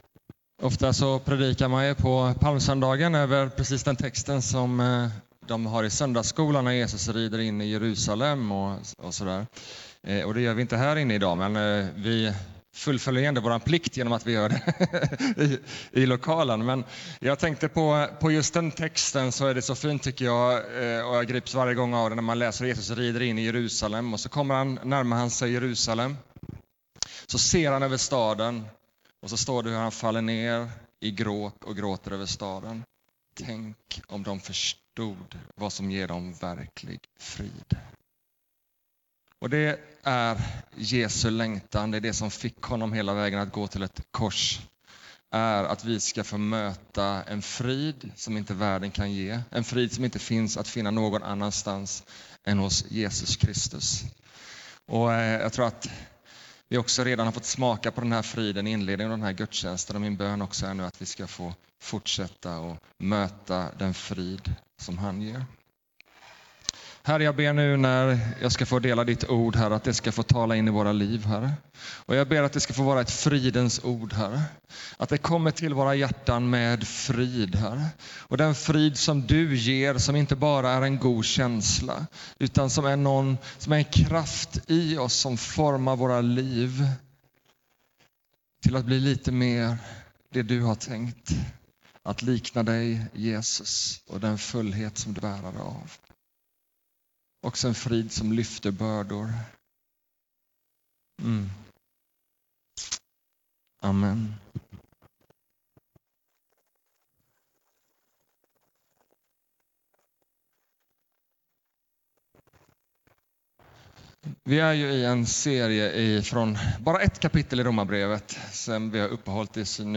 Launch Sermon Player Predikan